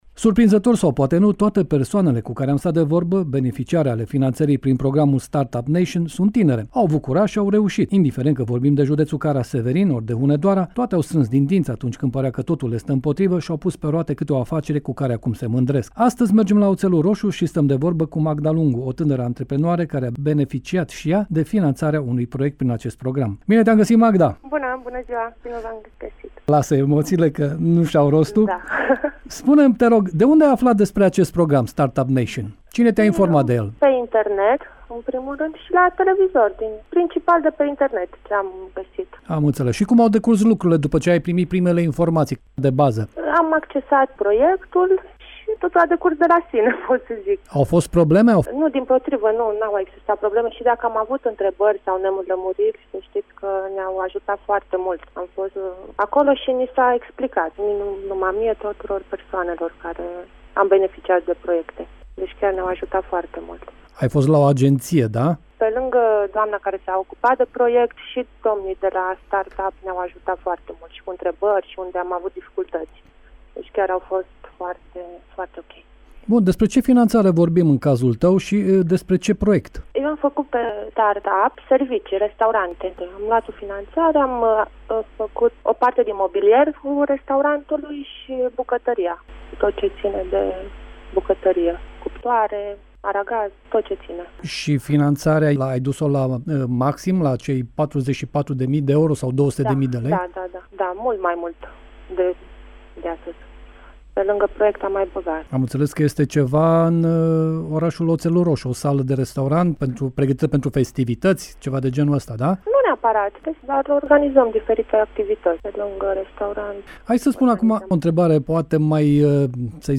Într-o serie de interviuri, la Radio Reşiţa, prezentăm reușitele celor care au aplicat pentru a obține fonduri.